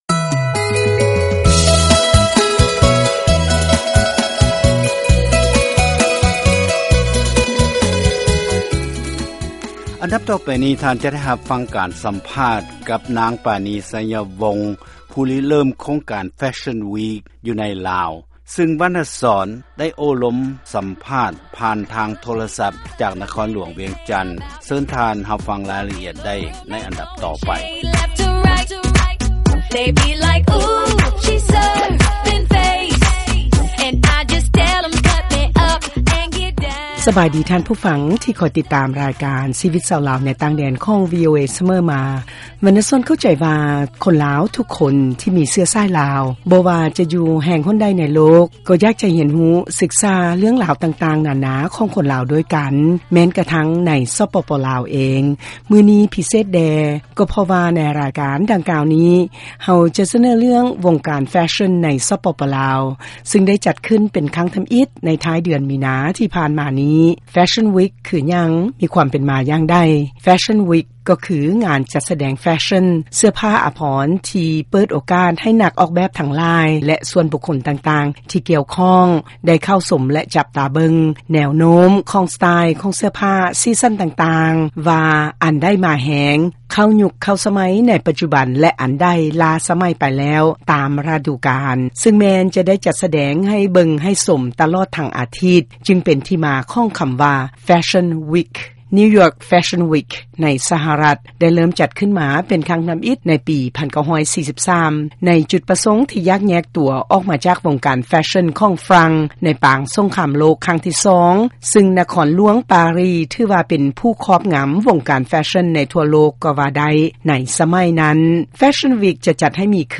ຟັງລາຍການສຳພາດກ່ຽວກັບ-Fashion-Week-03ເມສາ2014